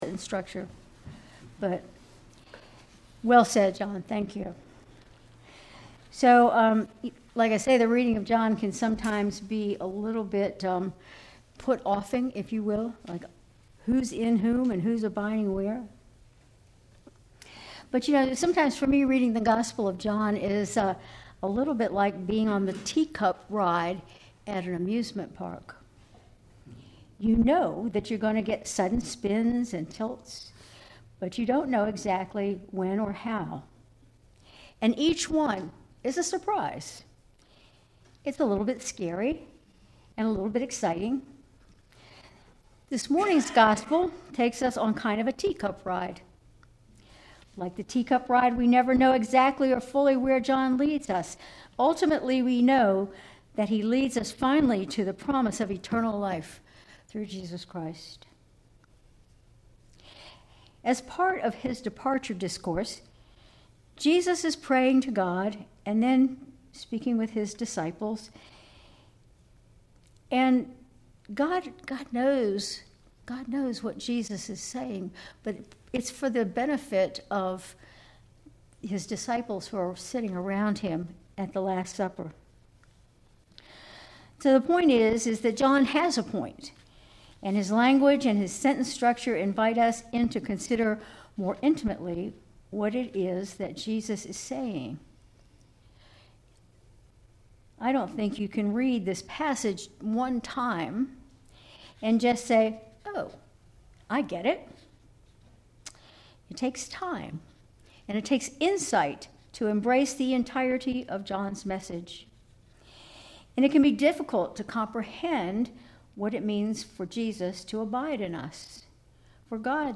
Sermon June 1, 2025
Sermon_June_1_2025.mp3